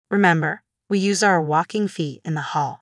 Select the audio clips to hear examples of a teacher stating clear expectations and of getting input from children on rules.